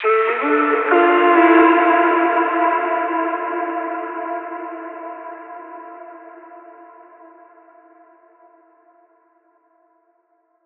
VR_vox_hit_deadinside_Dmin.wav